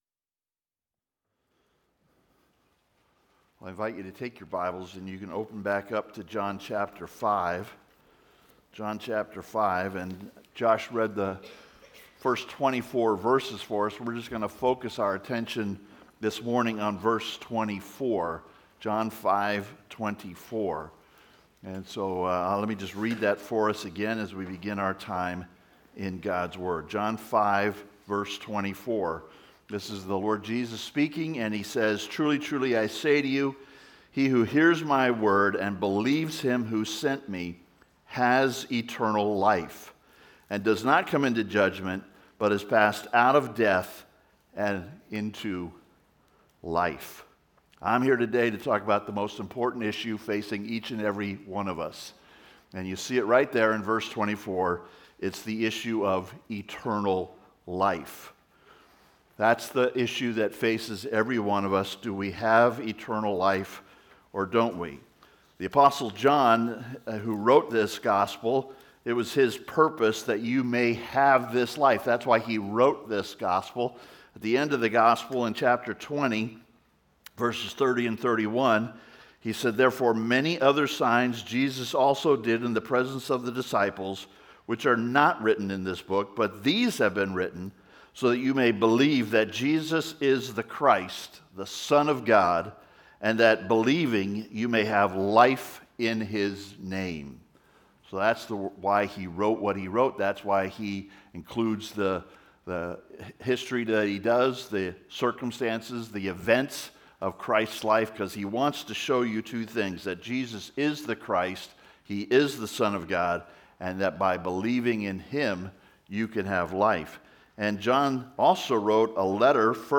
Eternal Life (Sermon) - Compass Bible Church Long Beach